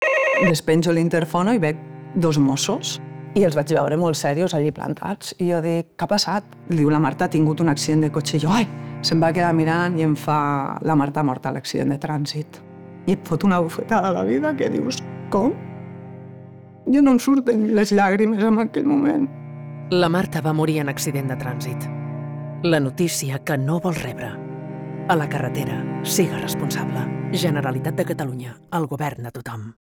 La noticia que no vols rebre_falca1.wav